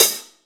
paiste hi hat5 close.wav